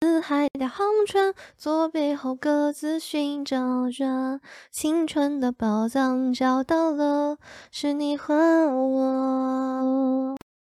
AI 全音域 沐瑶48K RVC模型
沐瑶，目前本站效果、音色最好的模型，在实时变声、男变女声的效果强悍，并非粗制滥造的免费模型。